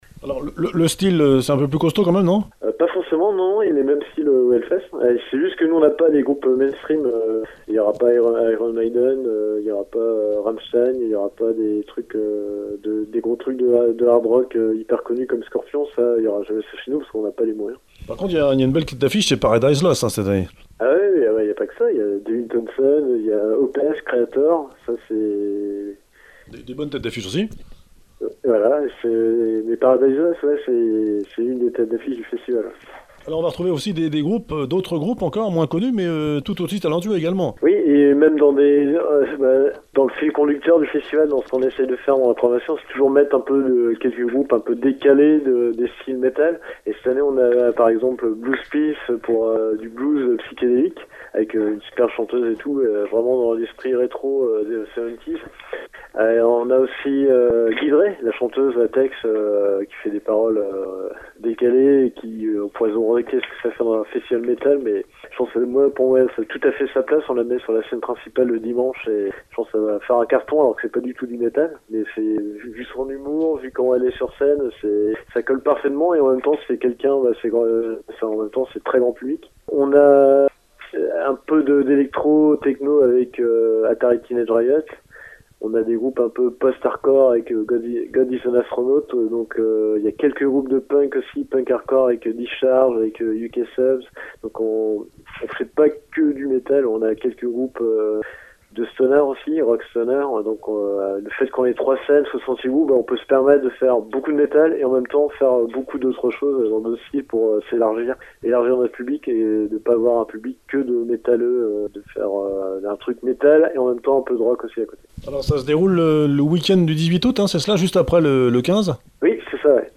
Le festival de Métal petit frère du grand HELLFEST de Clisson (44) ! Interview